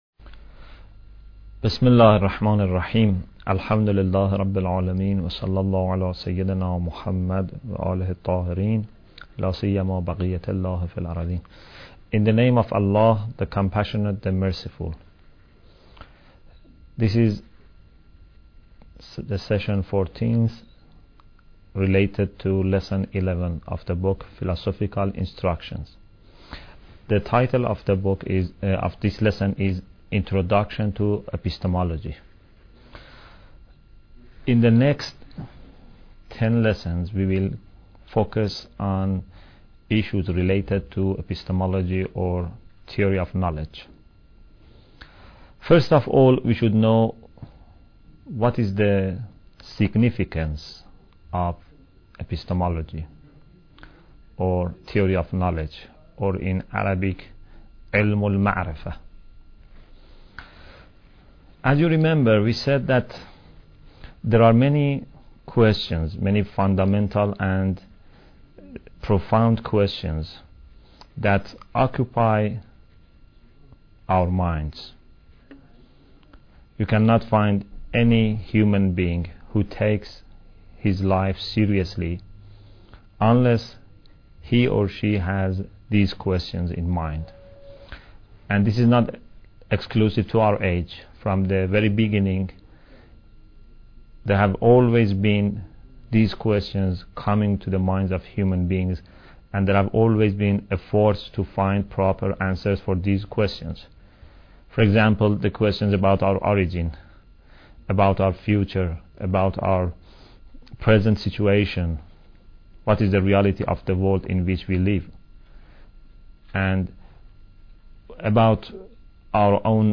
Bidayat Al Hikmah Lecture 14